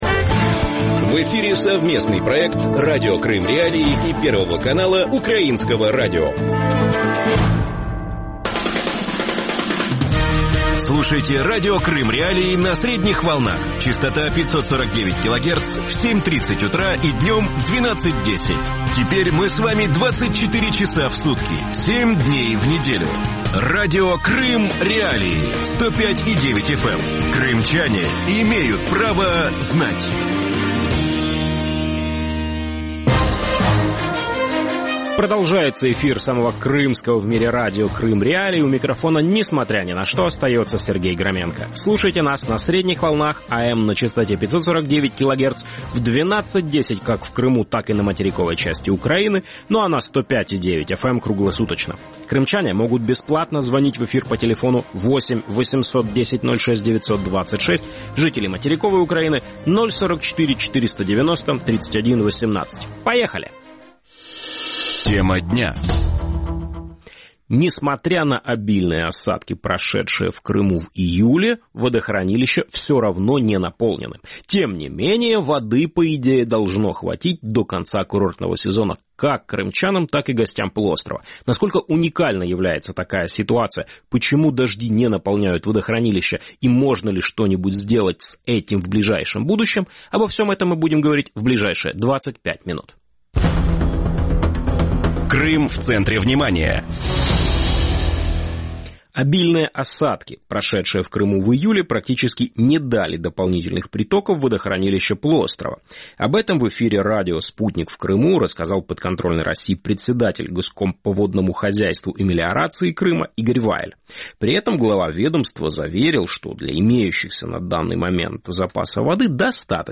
Радио Крым.Реалии вещает 24 часа в сутки на частоте 105.9 FM на северный Крым.